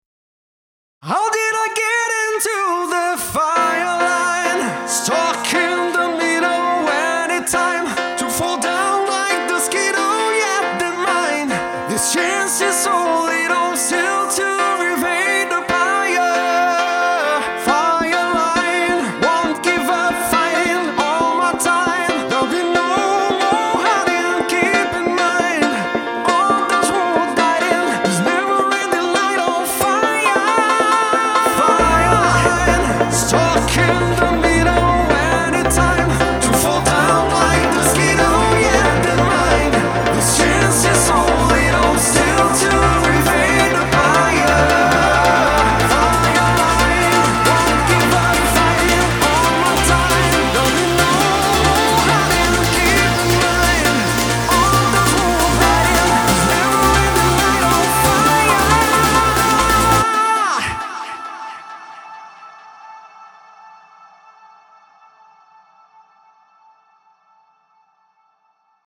EDM / Big Room / Festival Mucke